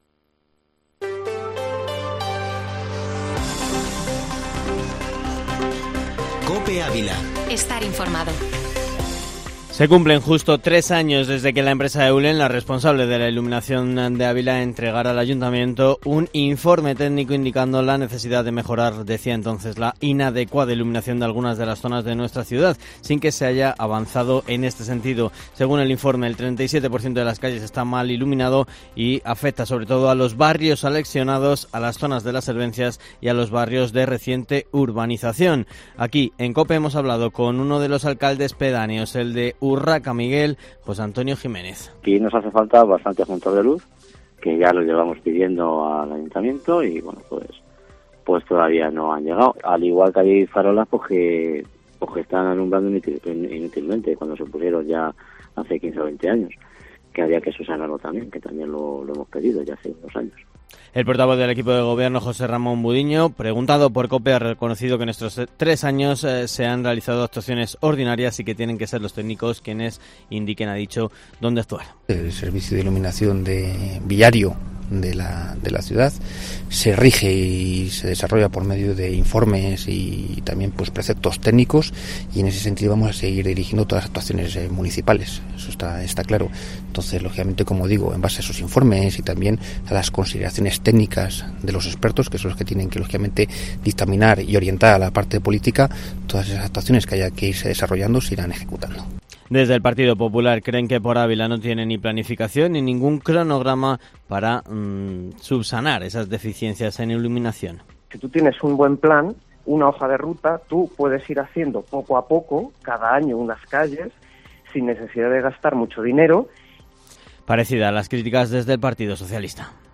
Informativo Matinal Herrera en COPE Ávila -31-agosto